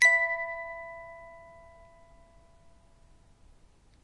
音乐盒的声音 " spieluhr7
描述：单次音乐盒音，由ZOOM H2记录，分离并归一化
标签： 铃铛 铃声 音乐
声道立体声